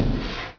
drag2.wav